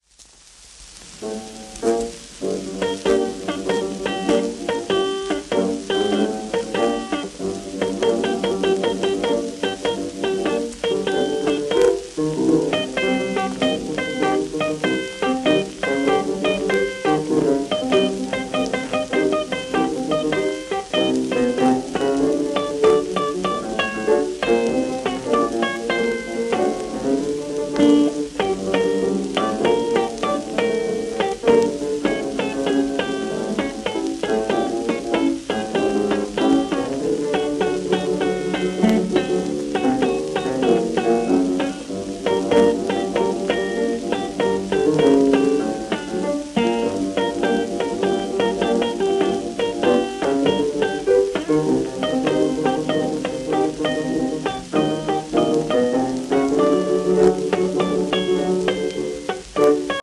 1928年頃の録音